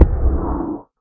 elder_hit2.ogg